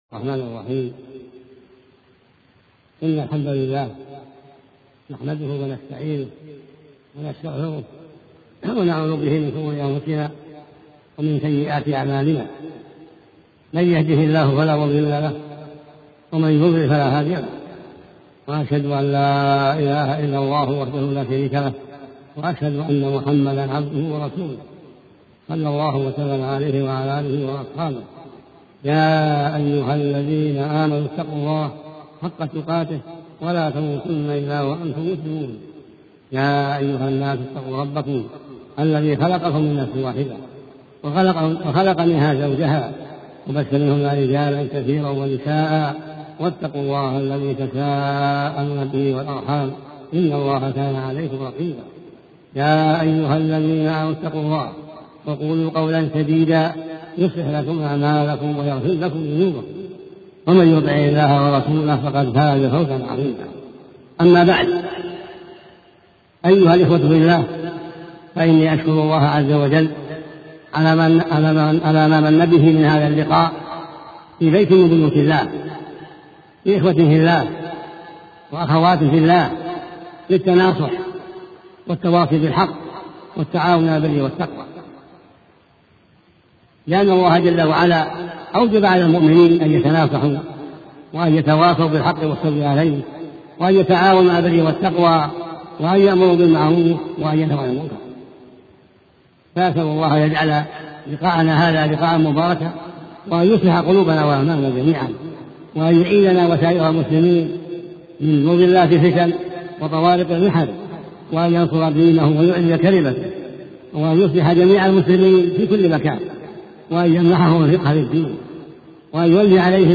شبكة المعرفة الإسلامية | الدروس | واجب أداء الأمانة |عبدالعزيز بن عبداللة بن باز